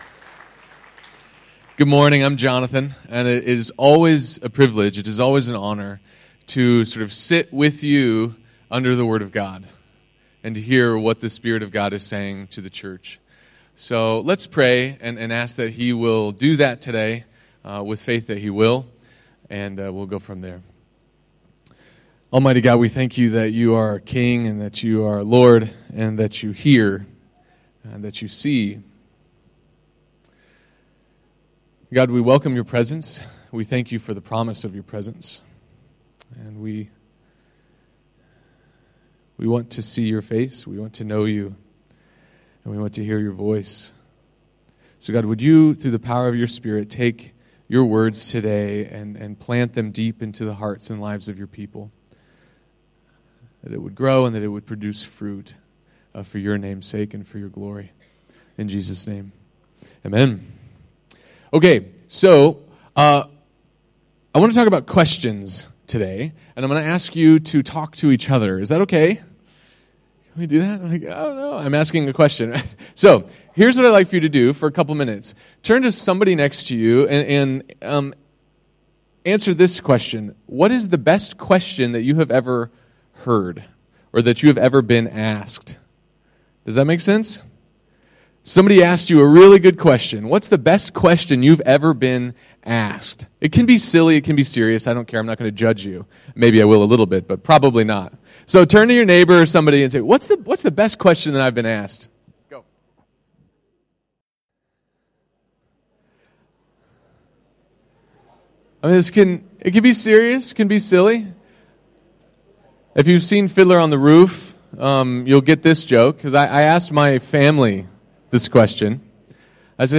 Recorded at ICA, Phnom Penh Cambodia, November 2017.